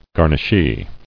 [gar·nish·ee]